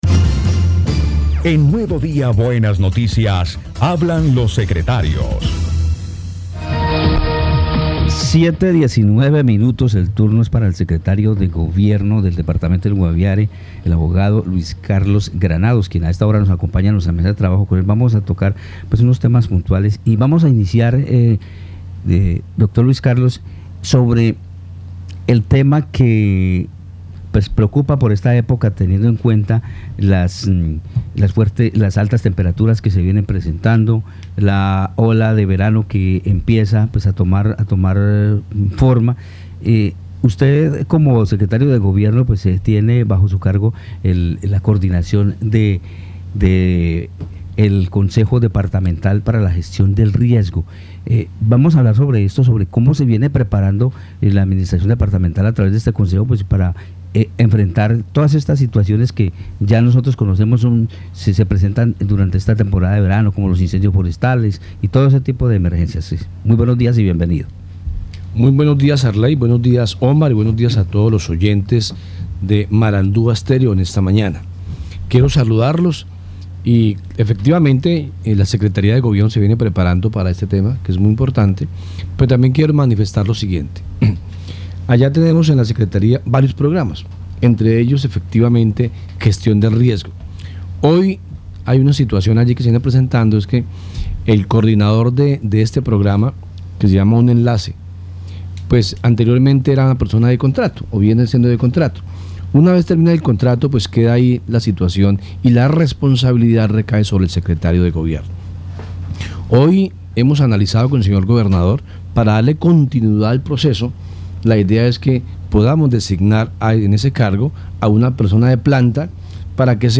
Escuche a Luis Carlos Granados, secretario de Gobiernno de Guaviare.